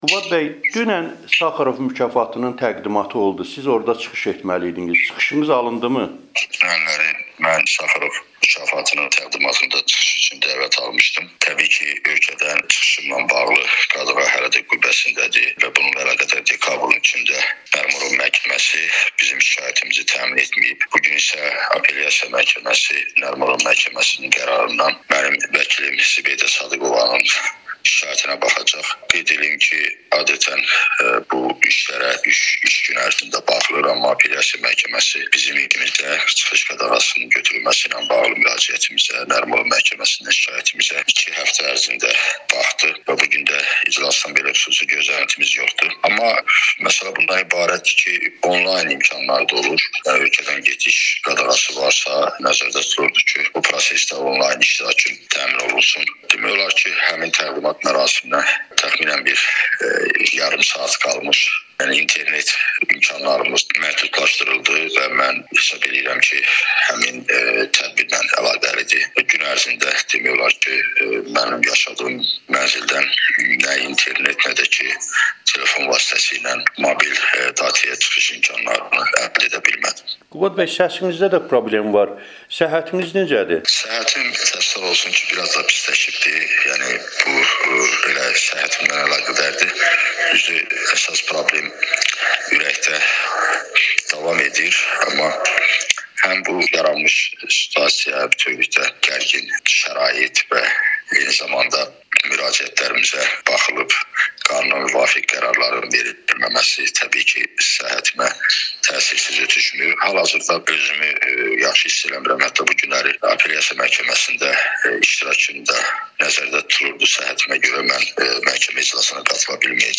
Hazırda ev dustaqlığında olan iqtisad elmləri doktoru Qubad İbadoğlu Amerikanın Səsinə müsahibəsində Avropa Parlamentində Saxarov mükafatının təqdimatında çıxışına imkan verilməməsindən, səhhətindəki problemlərdən və xaricə çıxışına qoyulmuş qadağa ilə bağlı məhkəmə baxışlarından danışıb.